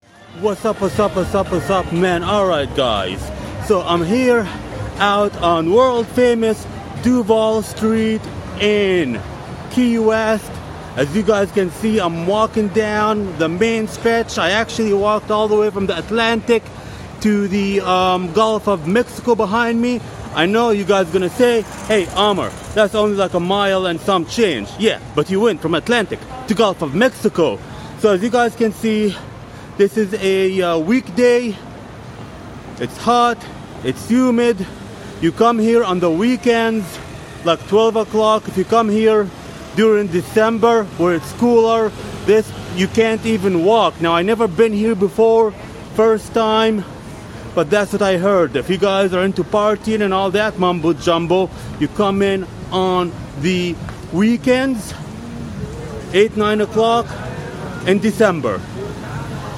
Walking Down World Famous Duval St In Key West